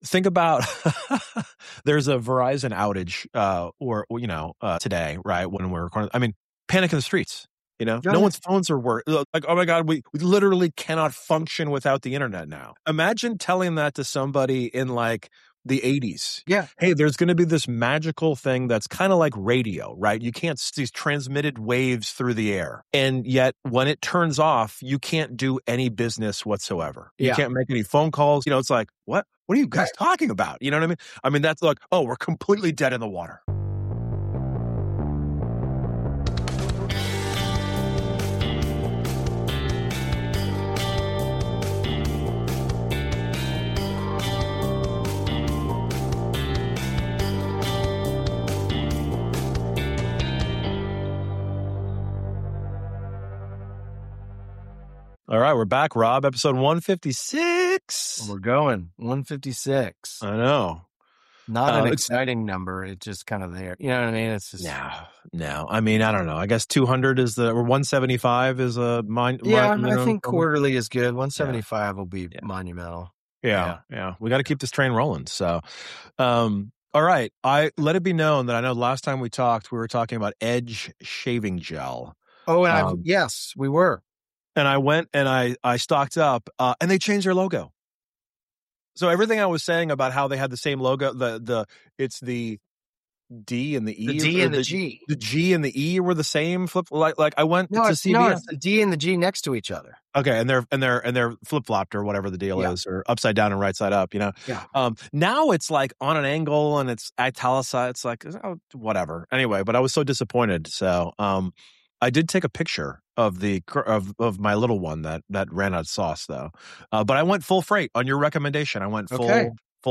This episode uncovers the evolution of technology and its impact on our daily lives, sharing personal stories and professional insights. With a blend of humor and expertise, they explore the importance of planning and success in web projects.
Tune in for an engaging discussion that will inform and entertain, offering a fresh perspective on technology's role in today's fast-paced world.